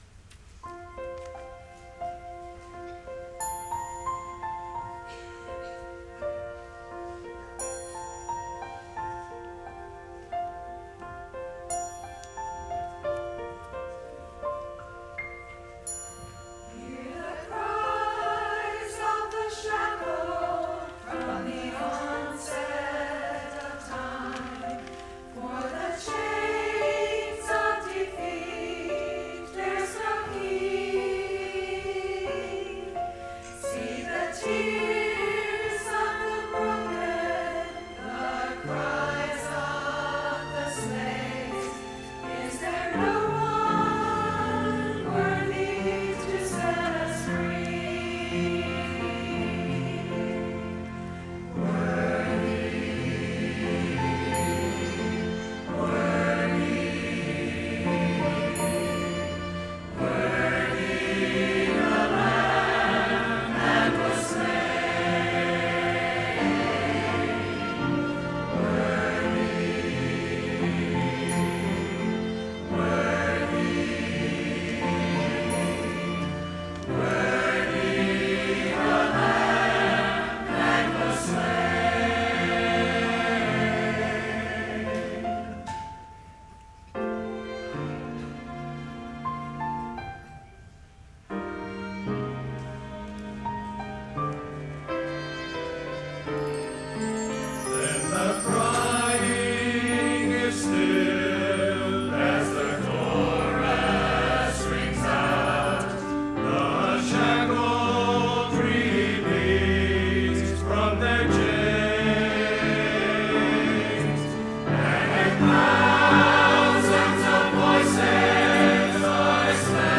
“Worthy The Lamb” ~ Choir at Faith Baptist Church
choir-worth-the-lamb.mp3